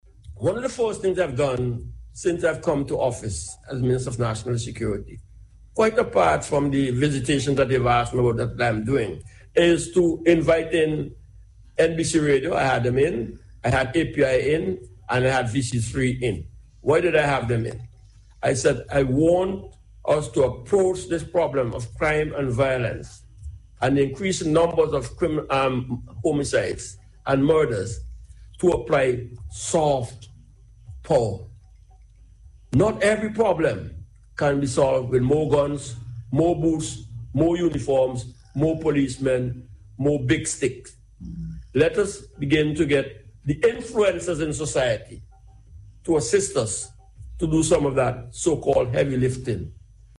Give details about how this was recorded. This was among a range of matters addressed by the Minister on Radio Thursday.